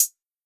Tm8_HatxPerc46.wav